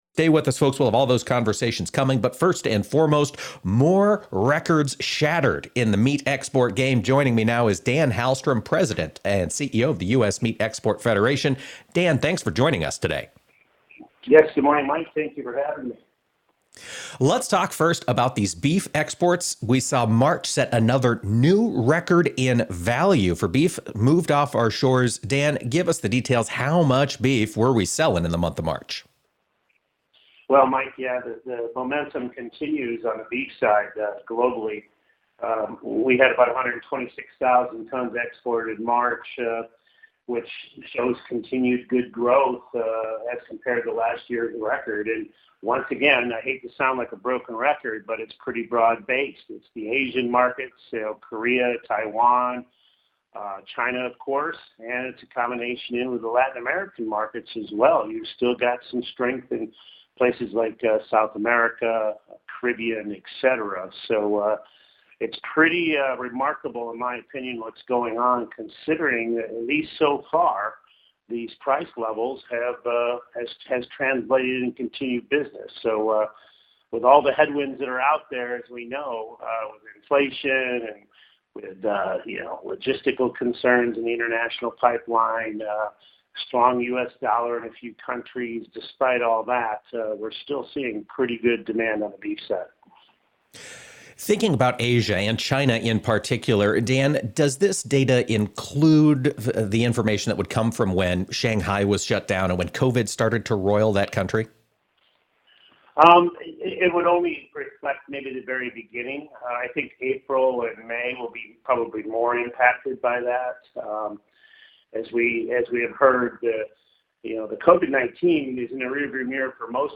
for a discussion of first quarter export results for U.S. beef, pork and lamb. Beef exports closed the quarter with a new monthly value record in March, topping $1 billion. March lamb exports were the third largest ever, with the highest value since 2014.